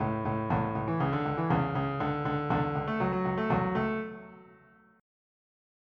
Loop MIDI Music File
reggae2.mp3